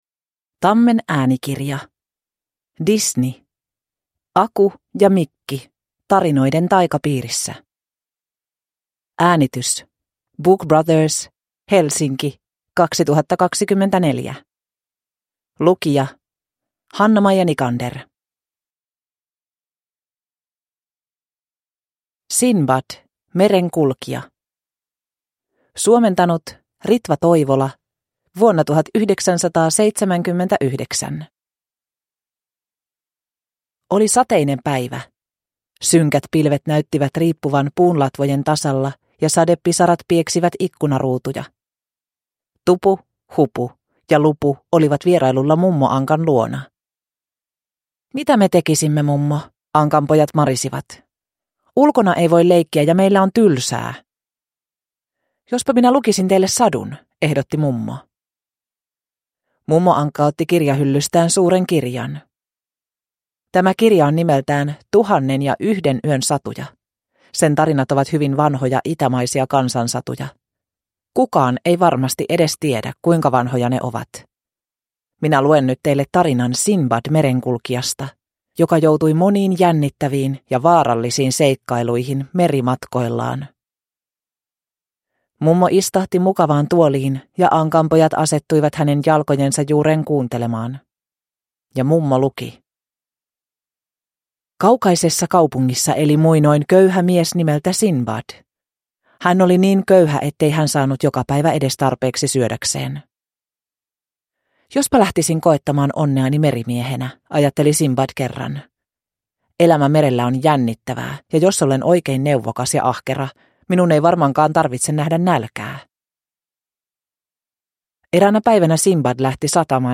Aku ja Mikki tarinoiden taikapiirissä – Ljudbok